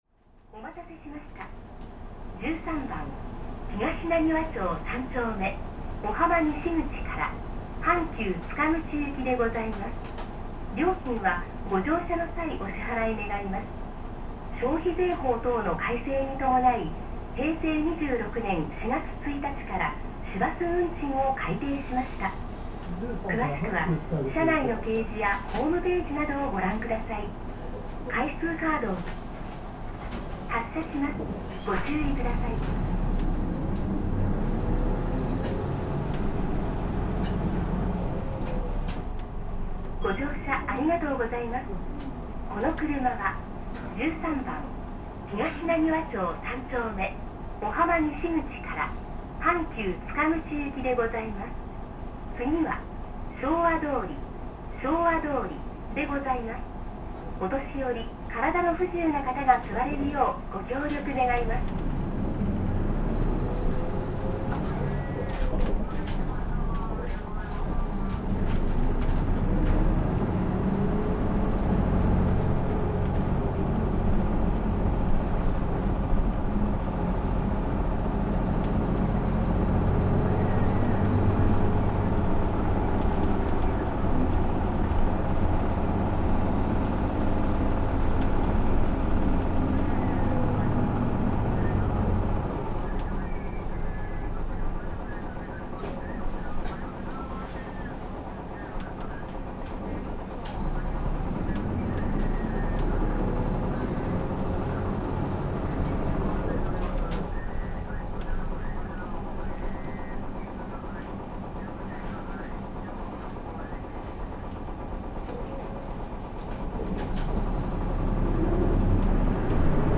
尼崎市交通局 三菱ふそう KL-MP37JK ・ 走行音(全区間) (37.7MB*) 収録区間：24系統 阪神杭瀬→JR尼崎→阪急園田 エアロスターKL代ノンステ。